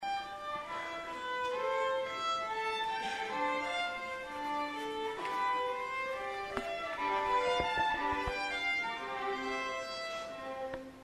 AT THE ORPHEUM THEATRE WEDNESDAY MORNING BEFORE AN AUDIENCE OF NEARLY 2000 AREA 8TH GRADE STUDENTS.
VIOLIN-MUSIC.mp3